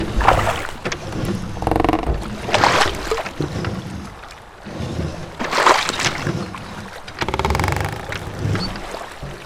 Row Boat - Loop.wav